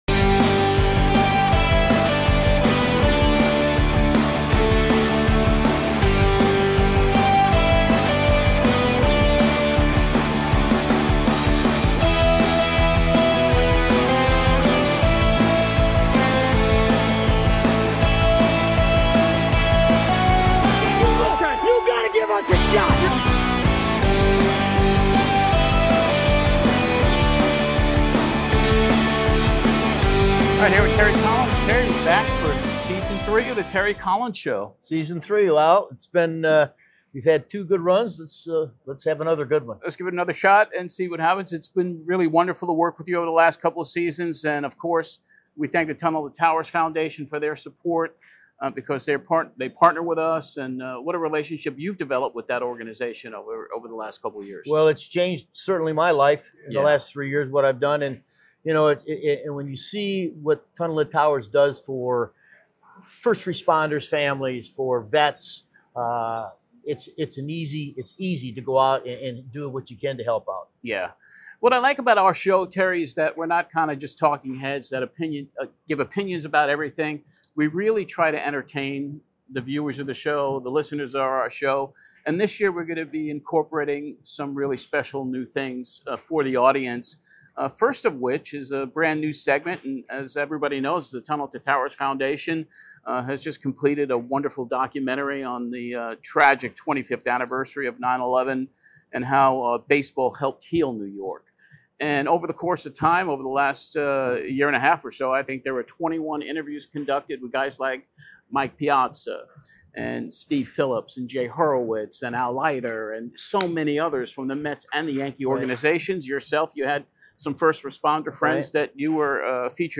On this season preview the crew is in Port Saint Lucie for Mets Spring Training and gets Terry Collins review of the Mets 2025 off season and his thoughts on the 2026 re-vamped team.